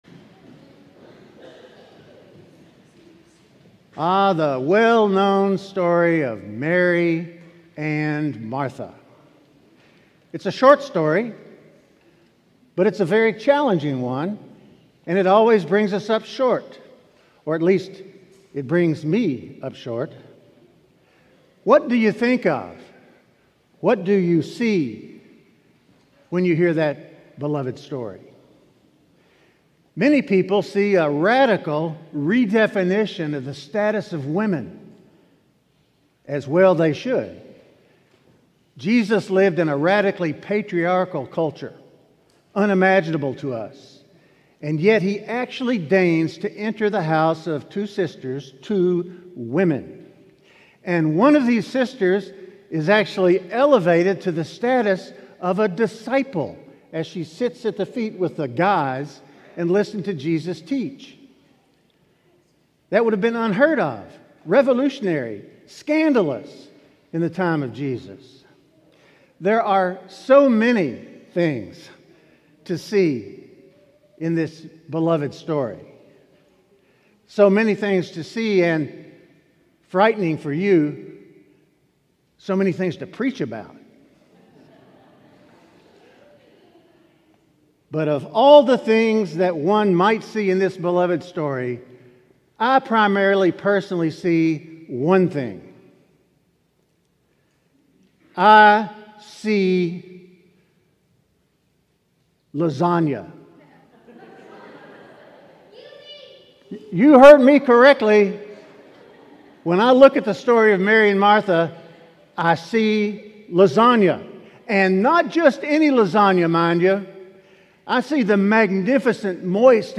Sermons from St. John's Cathedral Sermon: Consumed by the Immediate??